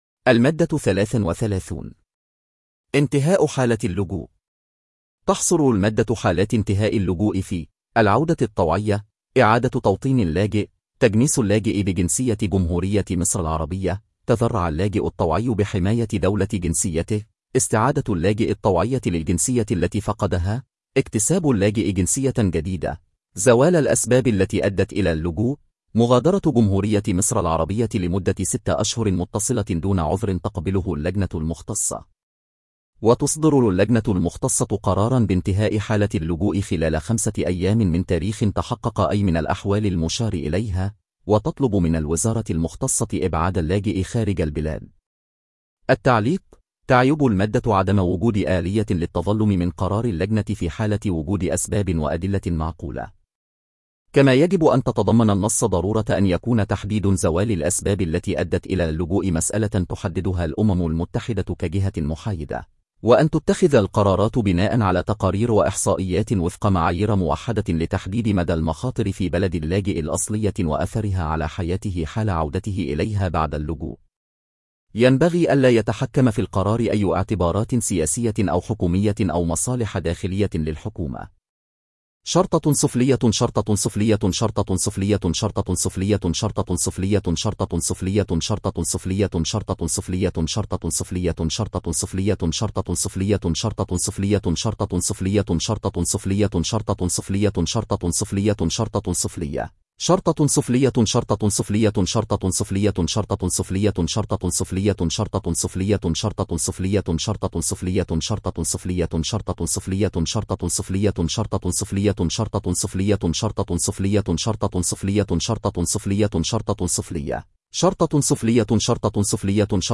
تنويه: هذه التسجيلات تمت باستخدام الذكاء الاصطناعي